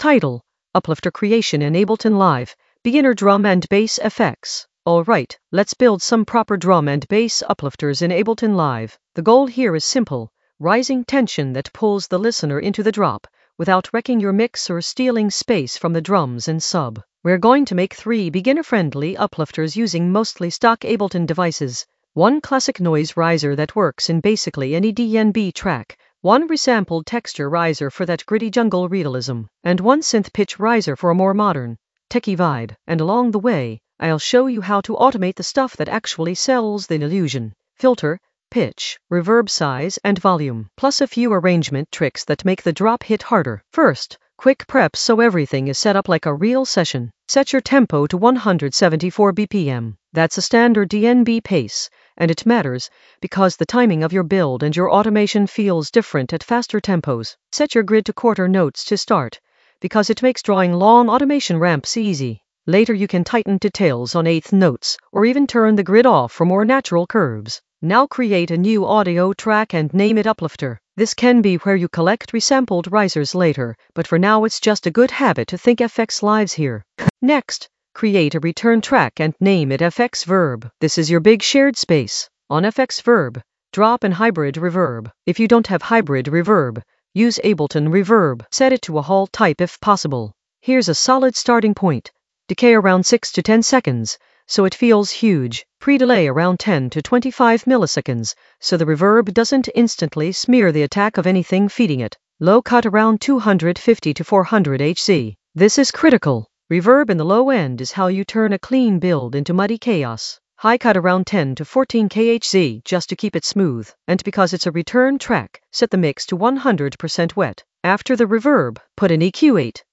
An AI-generated beginner Ableton lesson focused on Uplifter creation in the FX area of drum and bass production.
Narrated lesson audio
The voice track includes the tutorial plus extra teacher commentary.